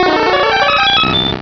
Cri de Mewtwo dans Pokémon Rubis et Saphir.
Cri_0150_RS.ogg